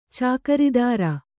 ♪ cākaridāra